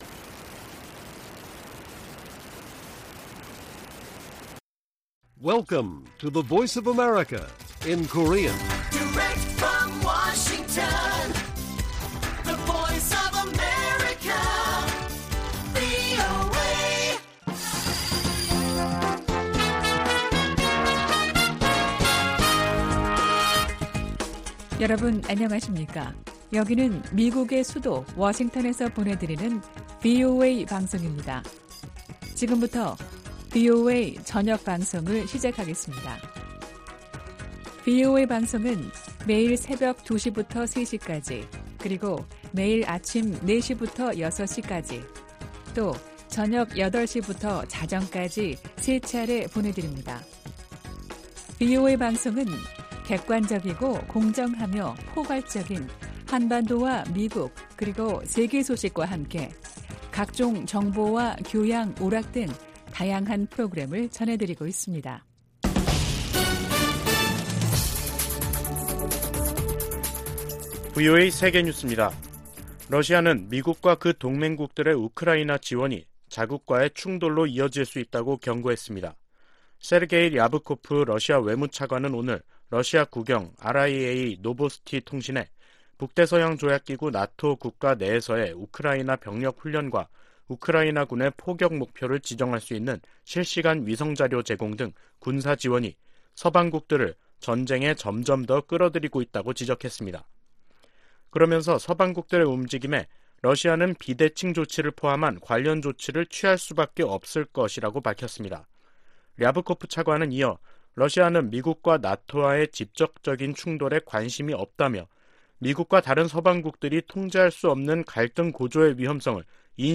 VOA 한국어 간판 뉴스 프로그램 '뉴스 투데이', 2022년 10월 11일 1부 방송입니다. 북한이 최근 핵전쟁 훈련을 벌인 것으로 확인되면서 향후 전술핵탄두 실험을 할 가능성이 높아졌다는 관측이 나오고 있습니다. 북한의 잇따른 탄도미사일 도발에 대해 국제사회에선 주민들의 기본 권리부터 챙기라는 목소리가 커지고 있습니다. 북한 회사와 노동자들이 제재에도 불구하고 세계 각국에 남아 외화벌이를 계속하고 있다고 유엔 안보리 대북제재위 전문가패널이 밝혔습니다.